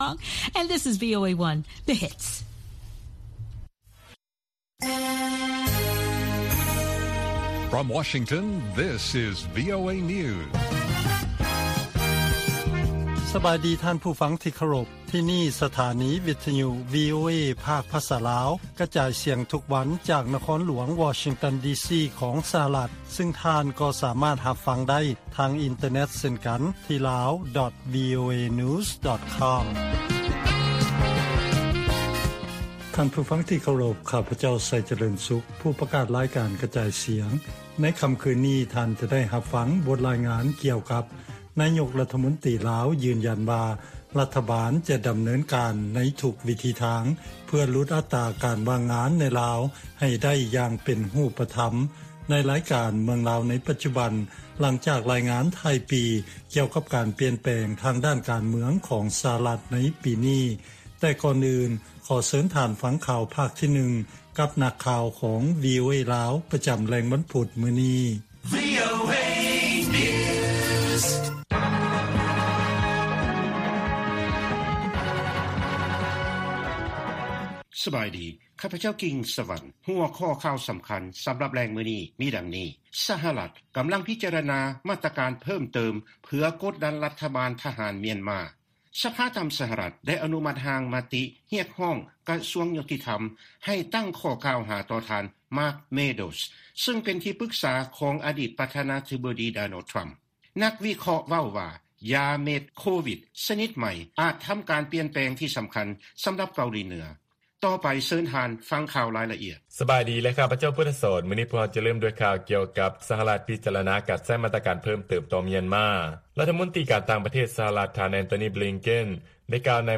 ລາຍການກະຈາຍສຽງຂອງວີໂອເອ ລາວ: ສະຫະລັດ ກໍາລັງພິຈາລະນາ ມາດຕະການເພີ່ມເຕີມ ເພື່ອກົດດັນ ລັດຖະບານທະຫານ ມຽນມາ